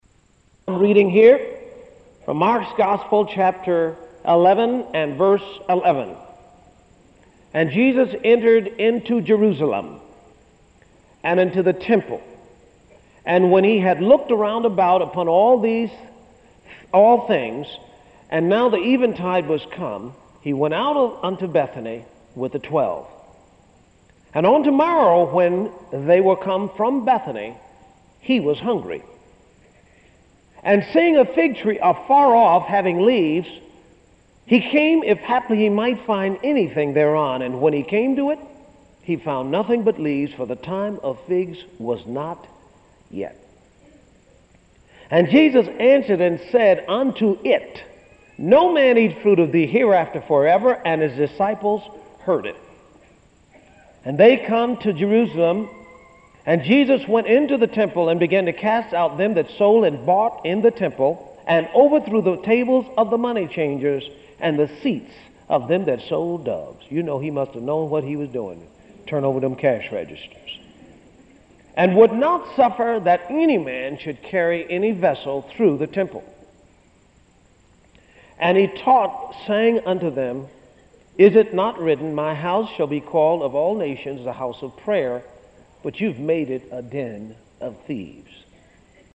In this dynamic teaching series you will learn how to use your faith to take hold of what belongs to you, why faith connects you to the supernatural so that you may dominate natural circumstances and much, much more!